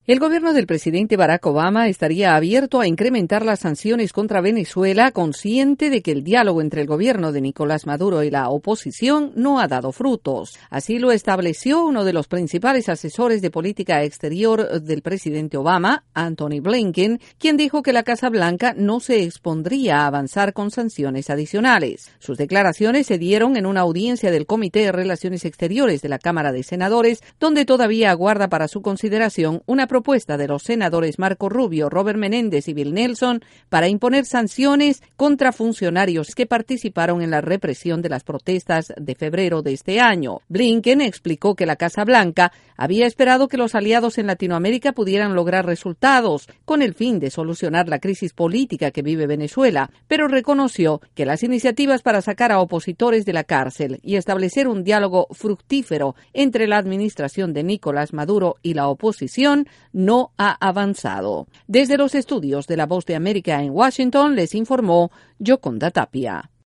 La Casa Blanca expone ante el senado estadounidense su posición sobre sanciones a Venezuela. Desde la Voz de América en Washington DC